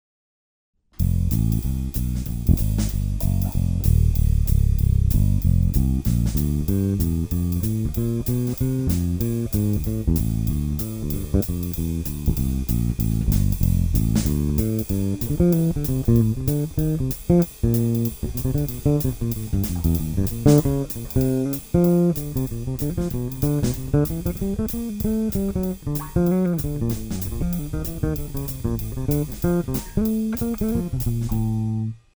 Bass Whisperer Tone Test:
They were recorded into ProTools direct using a passive Radial Engineering ProDI with Analysis Plus Bass Oval cable. No additional eq was used, and only minimal compression.
Jazz on the Sonus Std 5, both pickups, active mode.
Zon_SonusStd5_Act_Jazz_Both.mp3